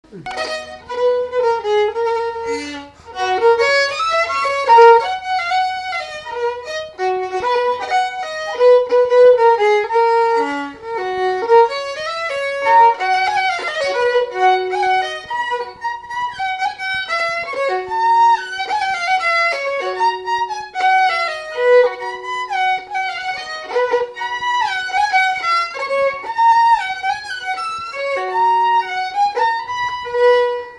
Séga
Instrumental
danse : séga
Pièce musicale inédite